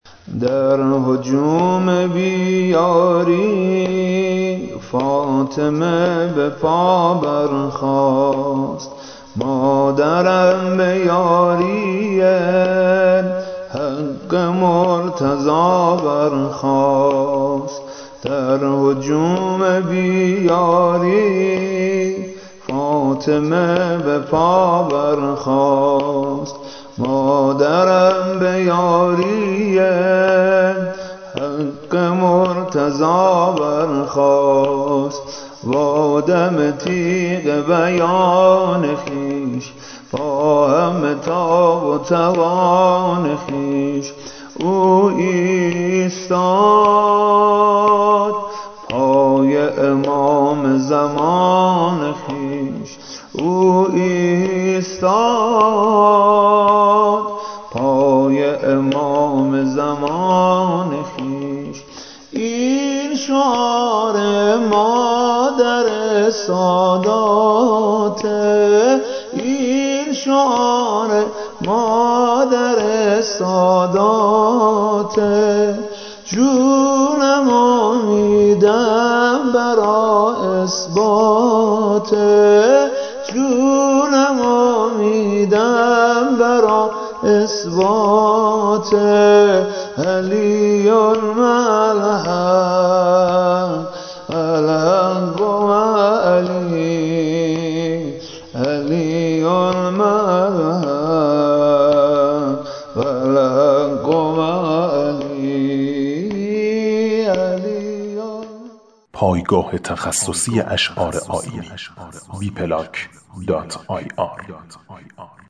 زمینه والحقُ معَ علی